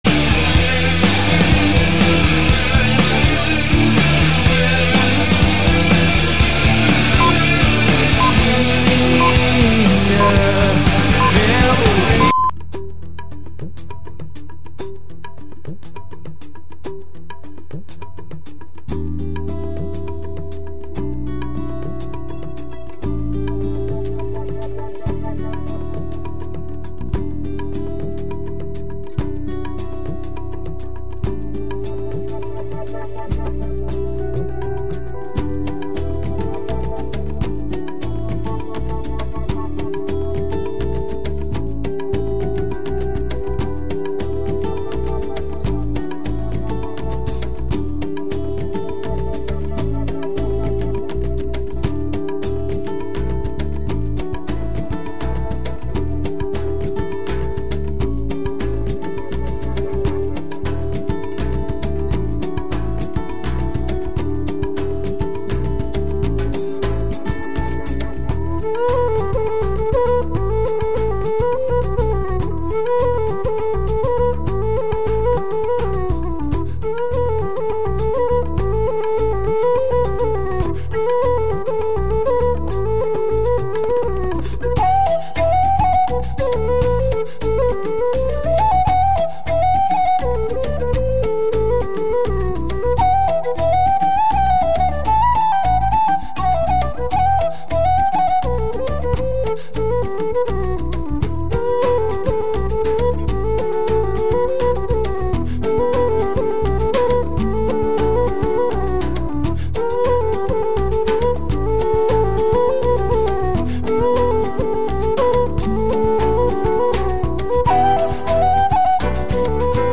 Recorreguts musicals pels racons del planeta, música amb arrels i de fusió.